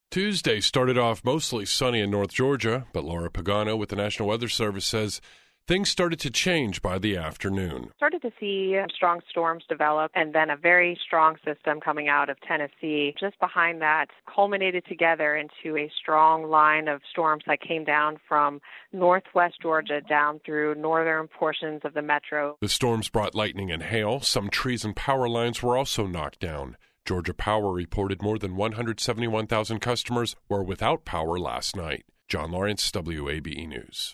An audio version of this story.